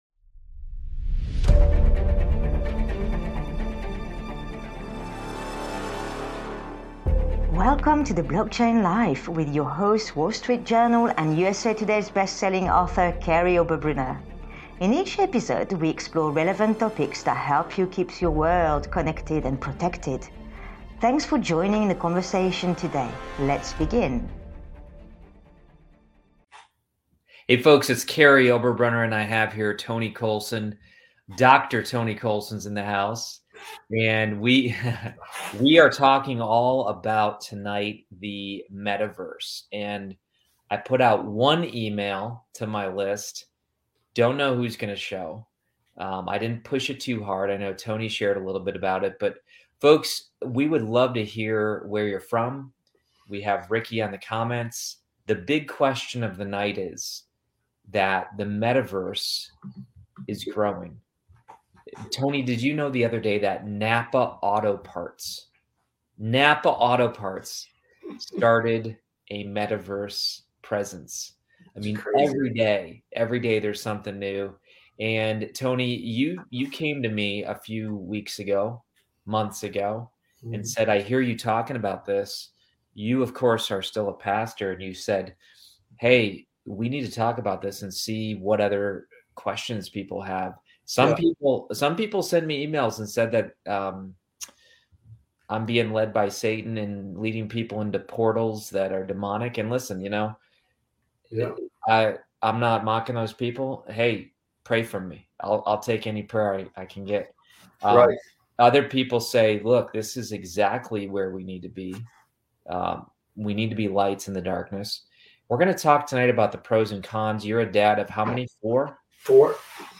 In this SPECIAL Livestream